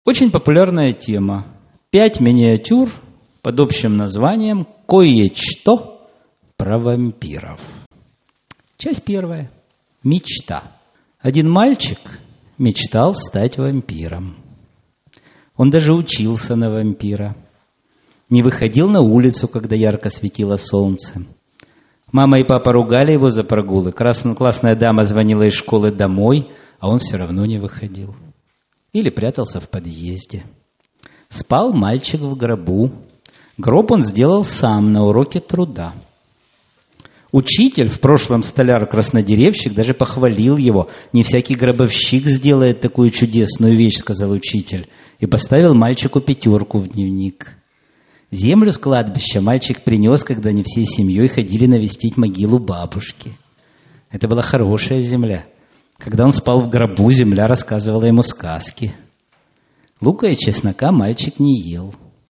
Аудиокнига Баллада о короткой дистанции | Библиотека аудиокниг
Aудиокнига Баллада о короткой дистанции Автор Группа авторов Читает аудиокнигу Олег Ладыженский.